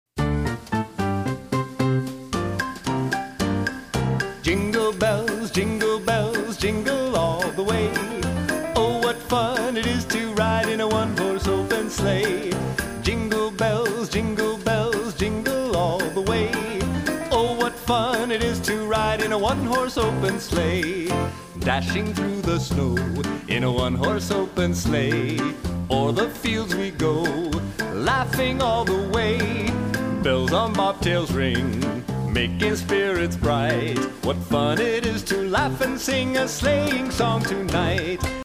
• Качество: 320, Stereo
праздничные
колокольчики
джаз
рождественские
40-е